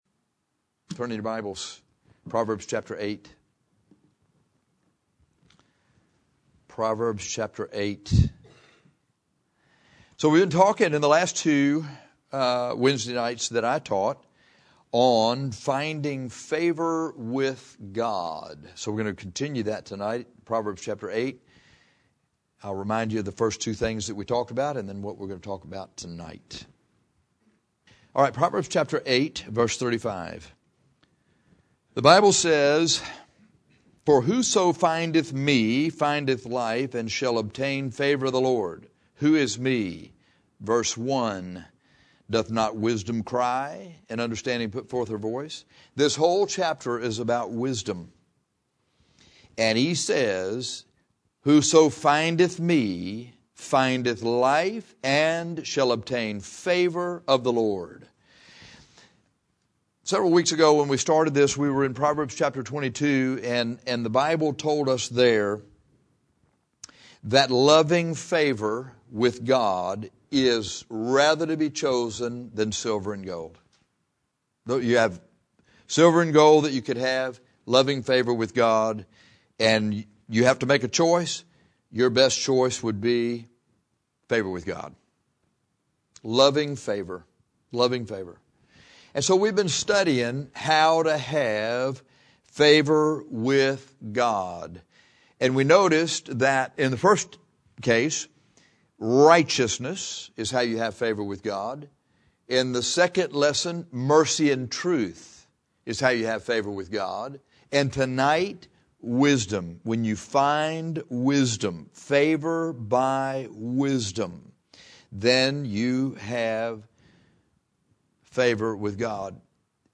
On recent Wednesday nights, we have been studying how to have favor with God.